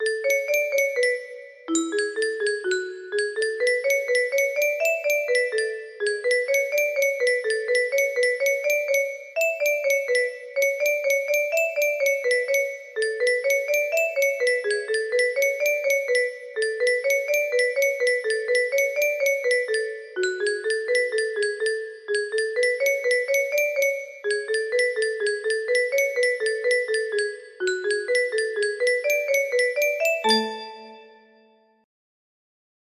Wandering through music box melody
Key: A maj
Tem.:125
Time:3/4